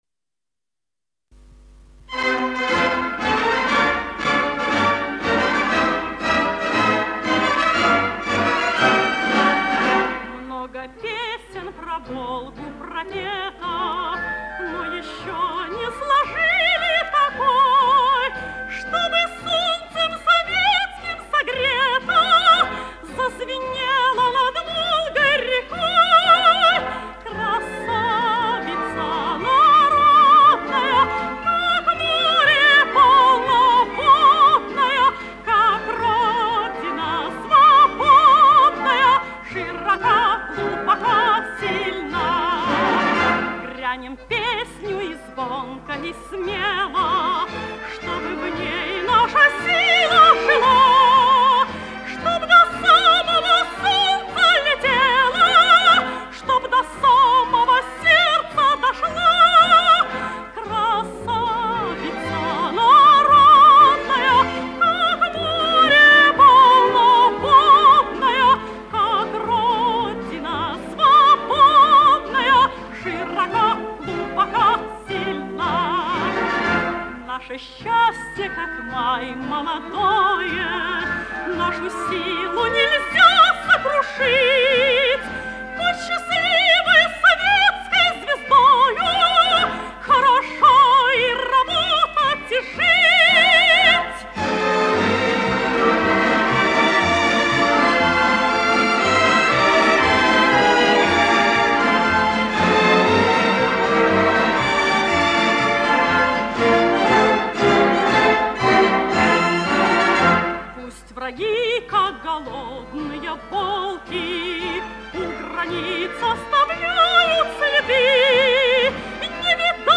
Источник фонотека Пензенского Дома радио